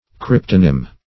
cryptonym.mp3